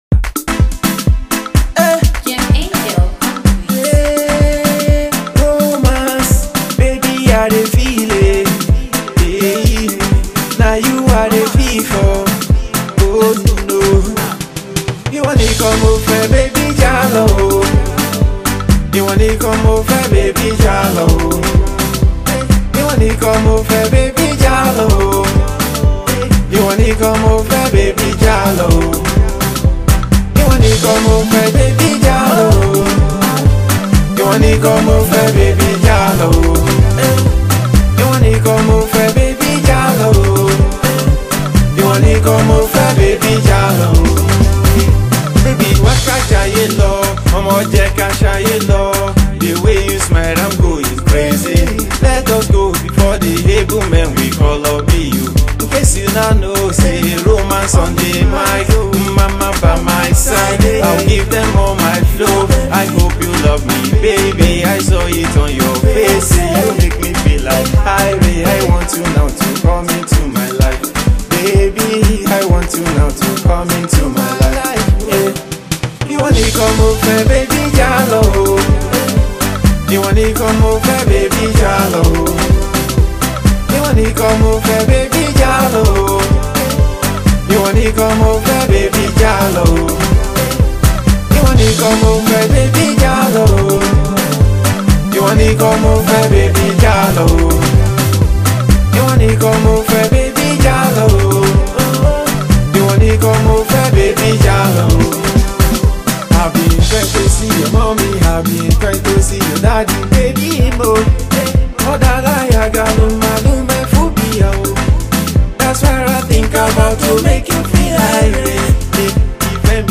Indigenous Pop
Yoruba Music
Love song